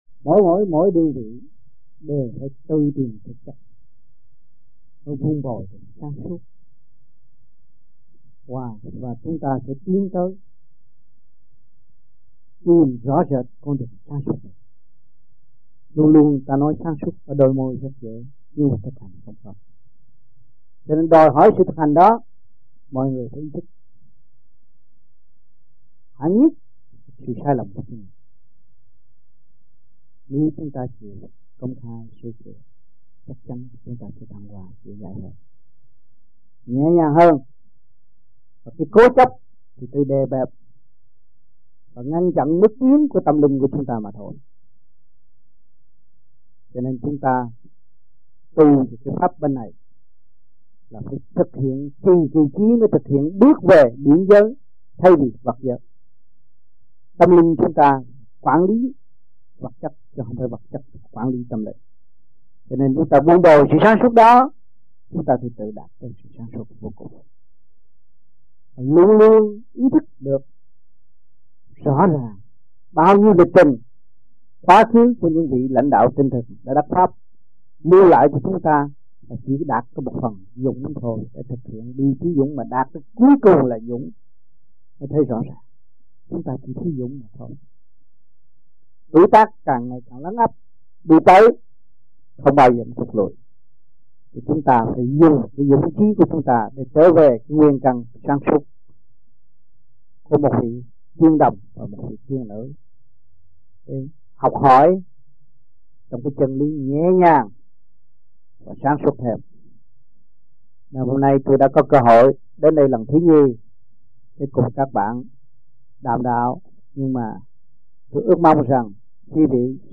United States Trong dịp : Sinh hoạt thiền đường >> wide display >> Downloads